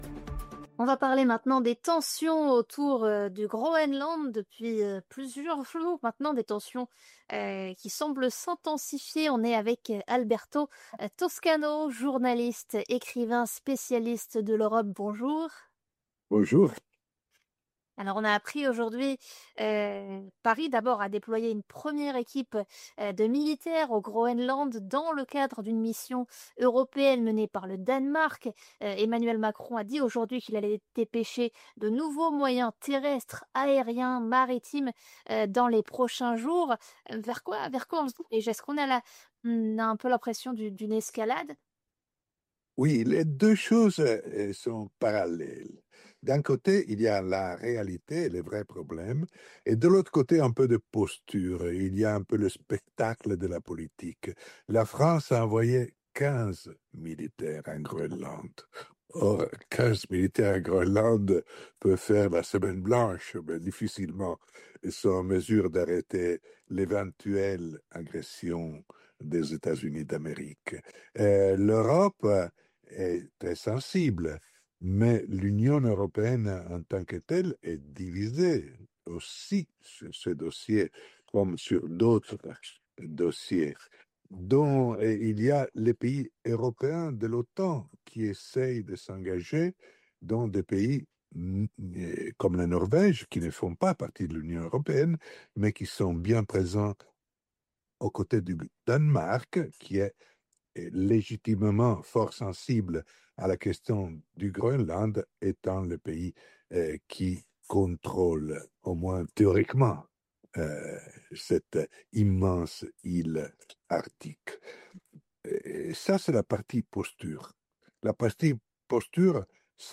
journaliste spécialiste des ffaires européennes